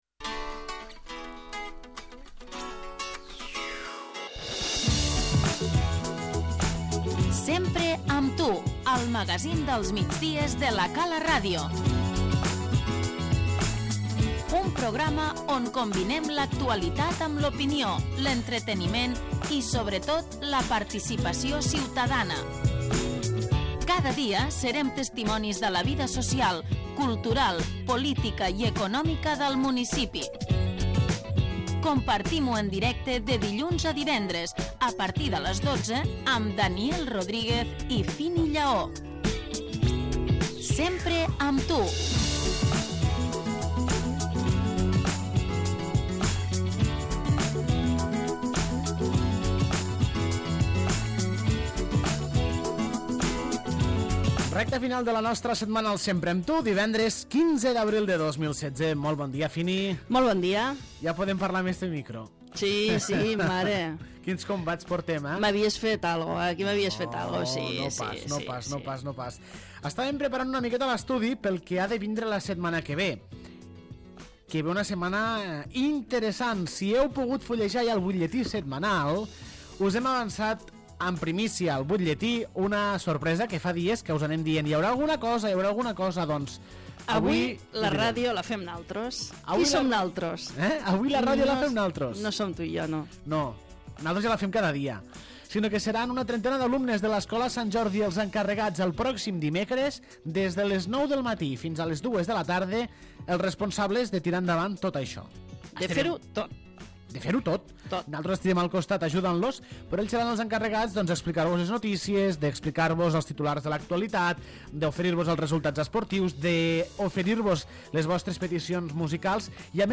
Entrevistem a Jordi Gaseni, alcalde de l'Ametlla de Mar.